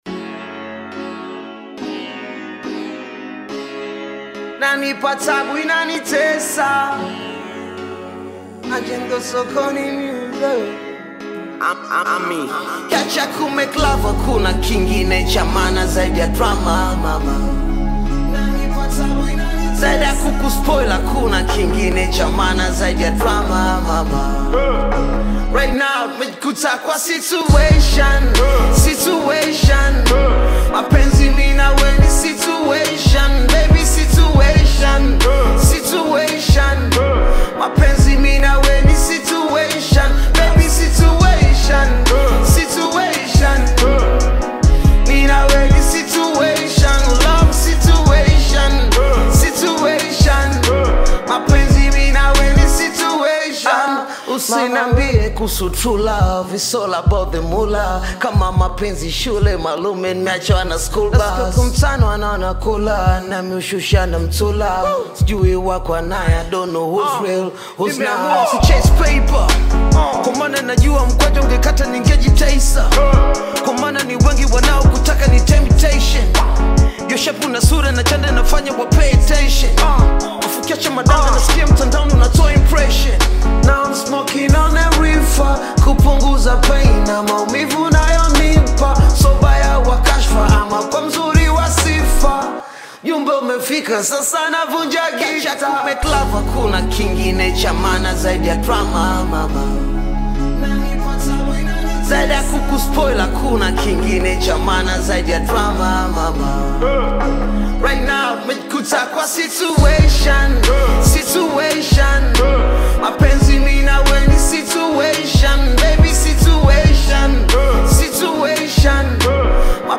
known for delivering clean sound and modern vibes.